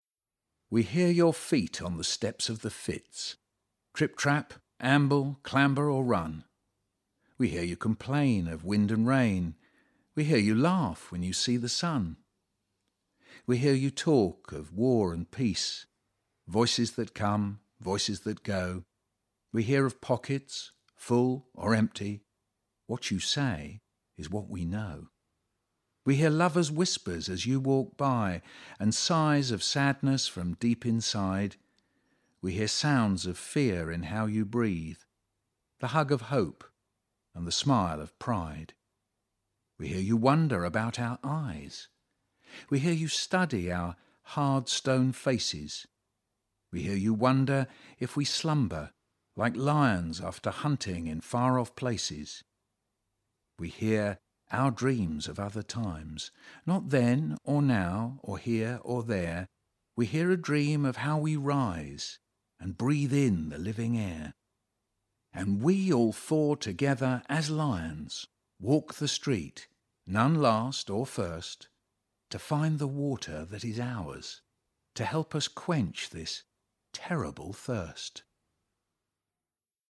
Michael Rosen reading 'Listening Lions'
This is a recording of Michael Rosen reading his poem 'Listening Lions', a poem commissioned for the Cycle of Songs Project.